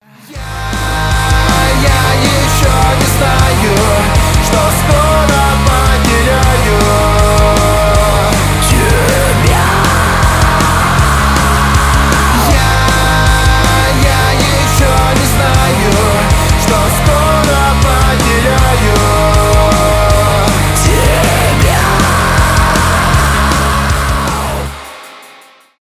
emo rock
панк-рок